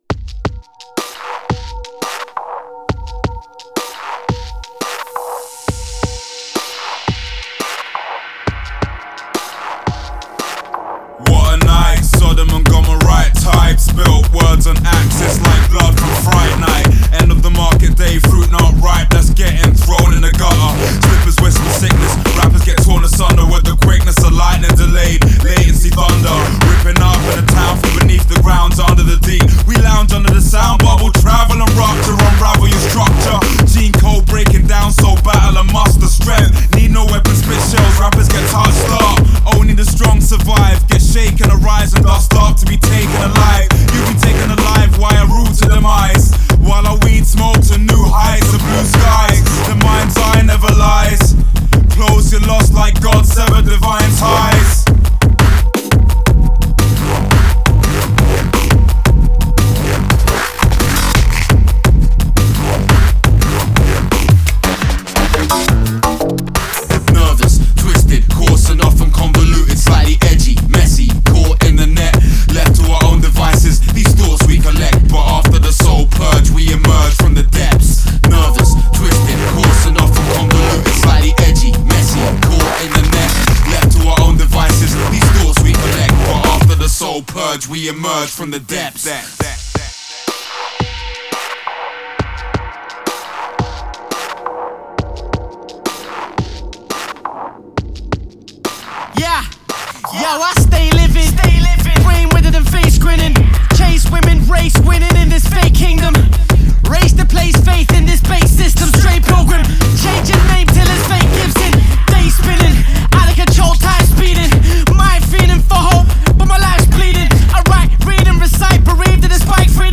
Style: Dubstep, Drum & Bass
Quality: 320 kbps / 44.1KHz / Full Stereo